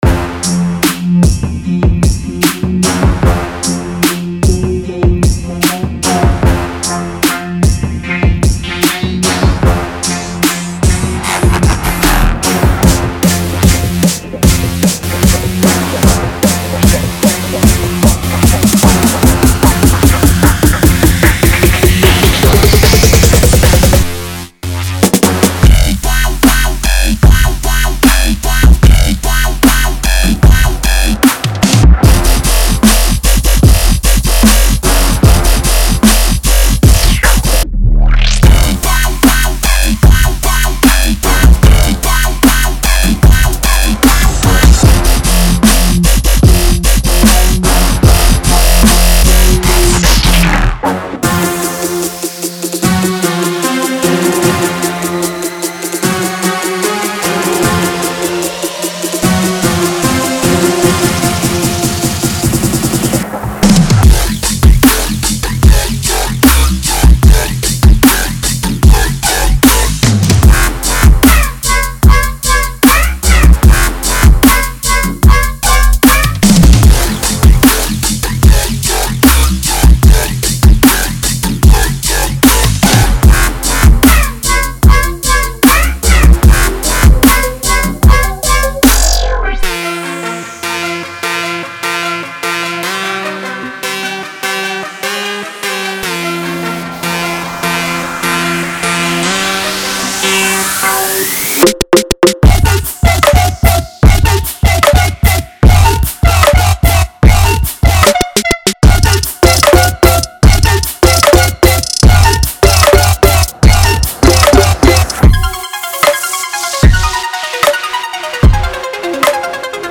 在这里，您会发现一些Neurofunk低音，陷阱/未来低音节拍，超炫的FX射击等等！
•150 BPM
•40个低音循环
•40个合成循环
•40个鼓循环
•20个喇叭循环